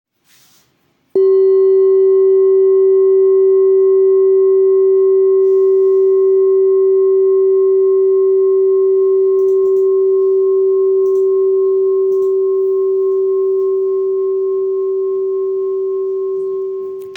Singing Bowl, Buddhist Hand Beaten, Moon Carved, Antique Finishing
Material Seven Bronze Metal
This is a Himalayas handmade full moon singing bowl. The full moon bowl is used in meditation for healing and relaxation sound therapy.